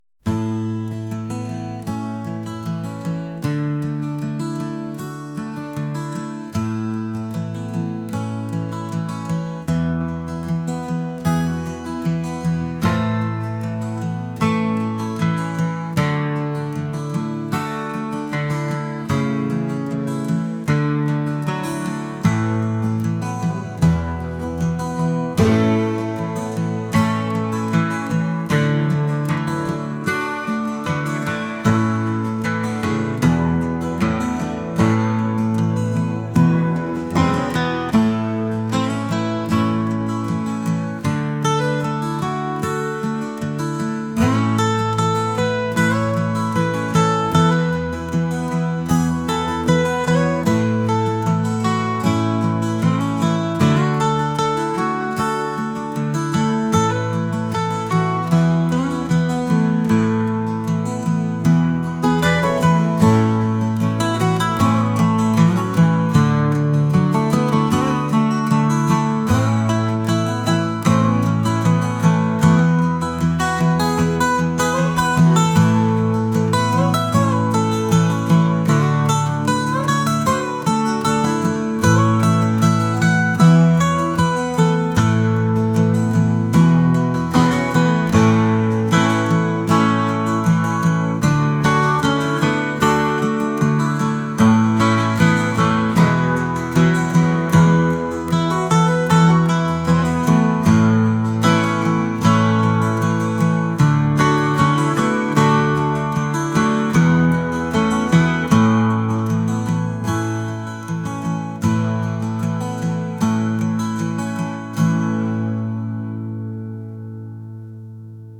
acoustic | folk | rock